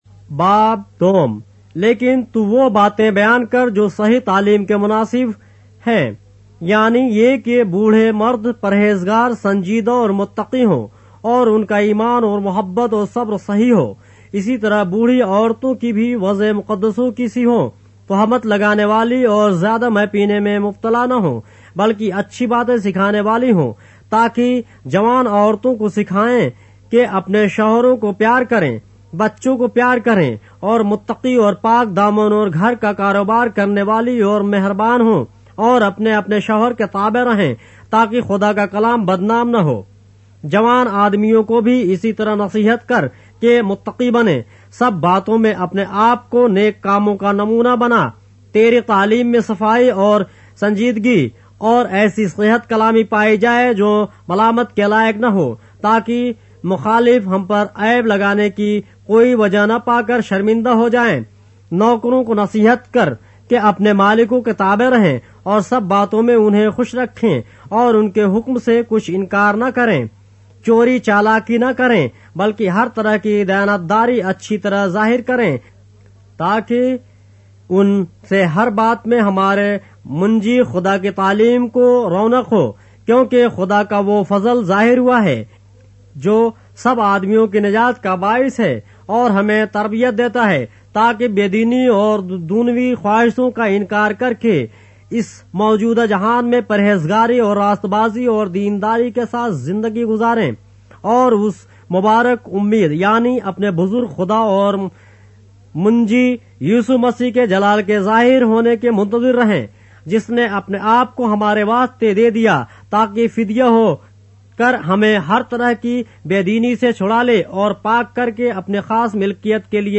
اردو بائبل کے باب - آڈیو روایت کے ساتھ - Titus, chapter 2 of the Holy Bible in Urdu